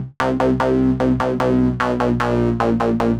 Index of /musicradar/future-rave-samples/150bpm